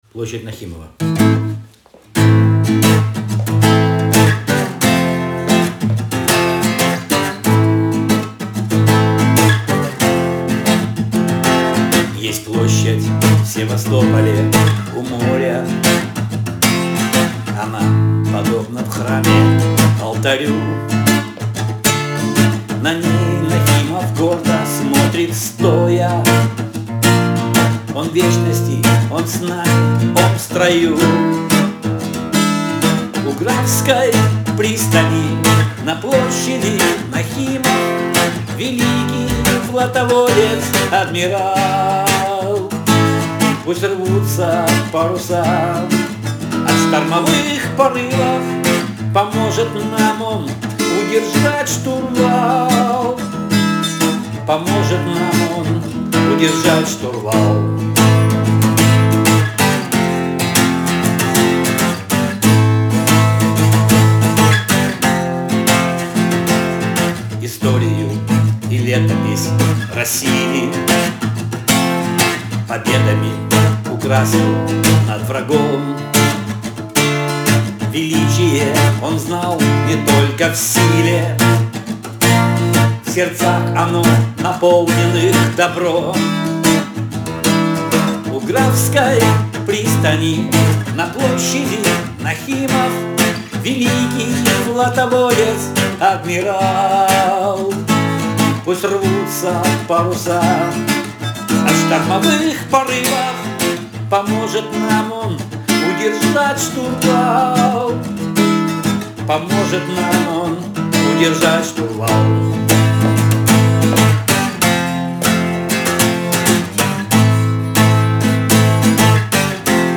Подноминация «Бардовская песня»